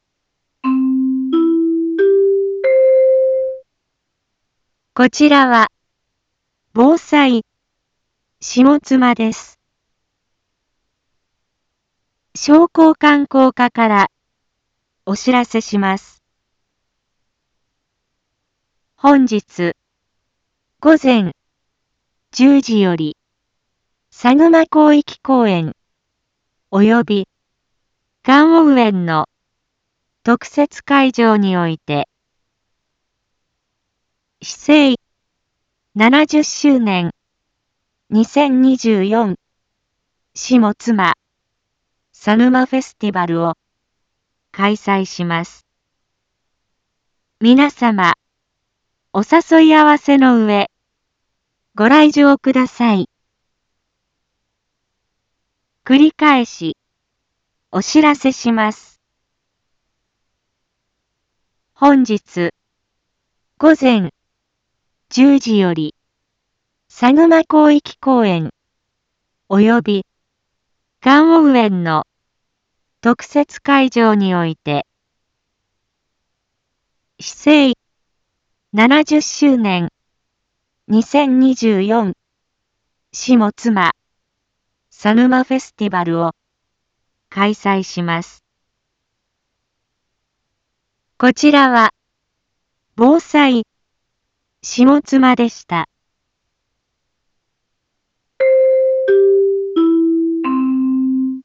一般放送情報
Back Home 一般放送情報 音声放送 再生 一般放送情報 登録日時：2024-10-20 09:01:46 タイトル：「2024しもつま砂沼フェスティバル」開催 インフォメーション：こちらは、ぼうさいしもつまです。